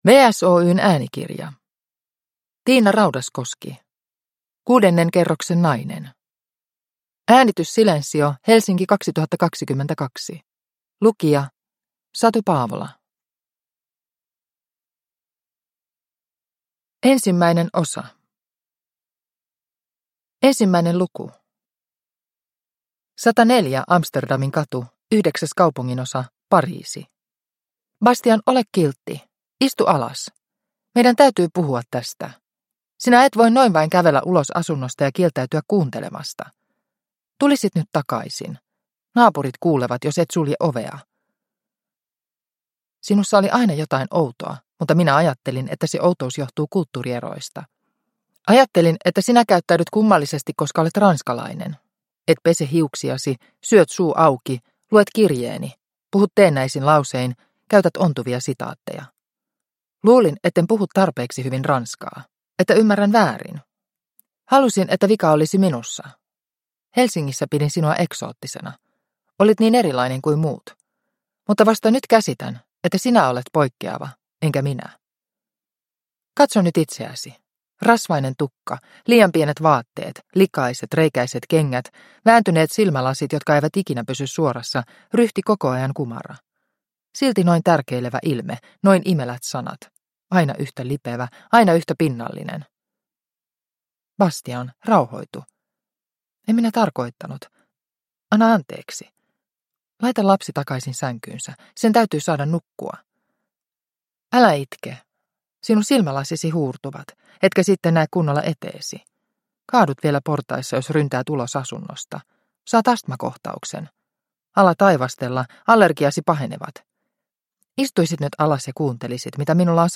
Kuudennen kerroksen nainen – Ljudbok – Laddas ner